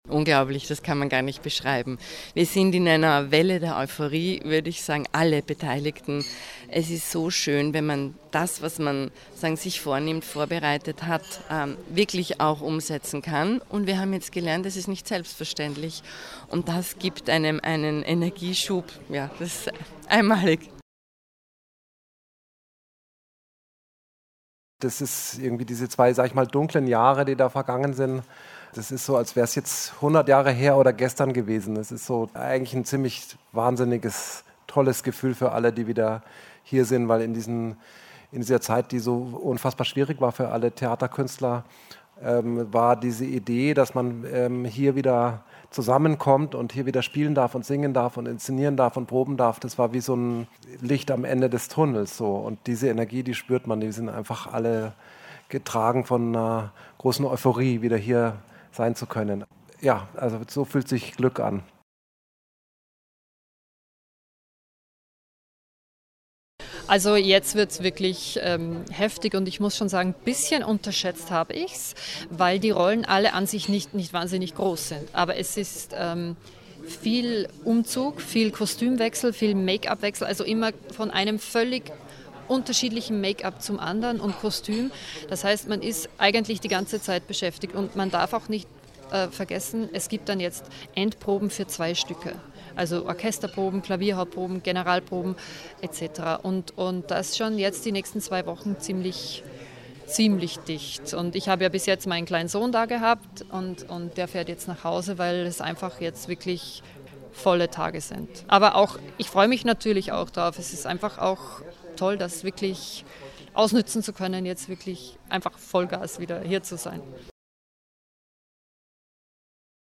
Pressekonferenz Pressetag 2021 feature